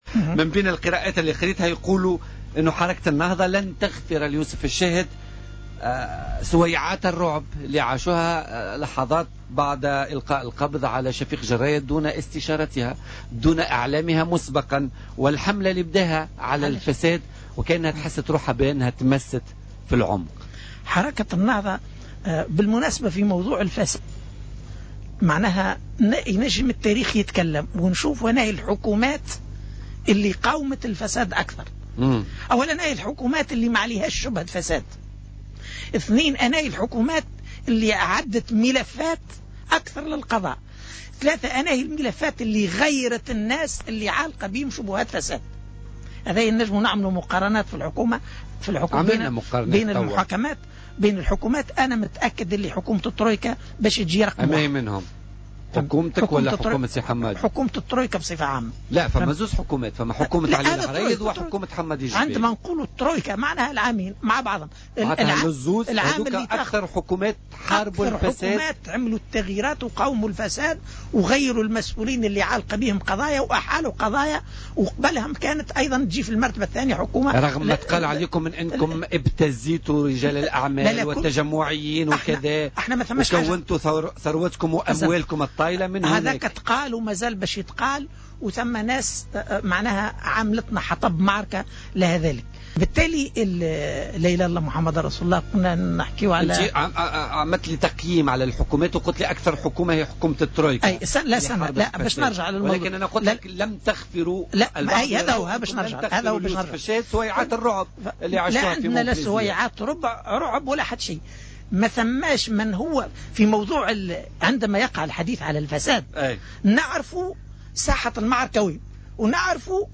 وأضاف ضيف "بوليتيكا" أمس الخميس، أن آفاق تونس يتجهم باستمرار على النهضة في الفترة الأخيرة ولم يحترم أصول الحكم المشترك، في حين أن لا أحدا يزايد عليها في مسألة مقاومة الفساد، وخاصة آفاق تونس.